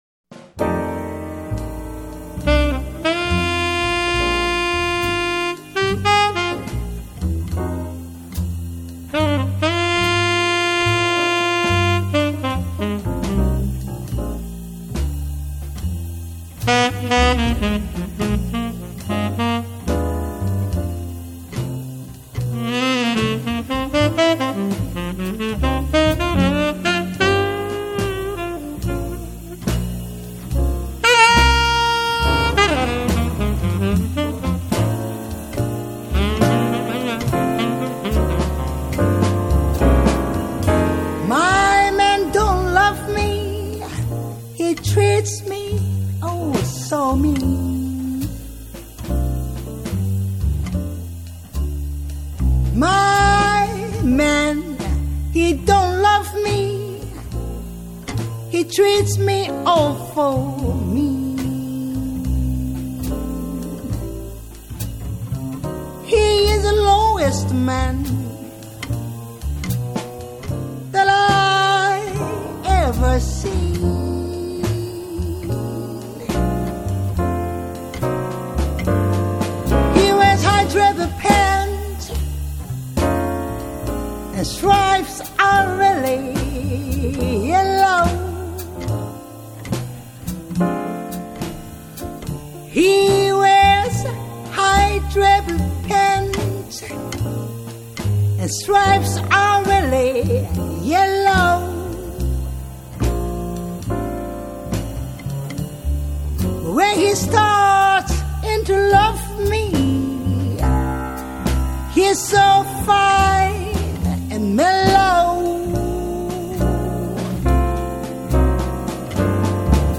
J-Jazz
對歌曲節奏掌握相當細膩，帶有豐富戲劇性，能夠輕易地牽動聽眾情緒。